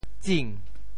潮州发音 潮州 zing2 文 ding2 白
tsing2.mp3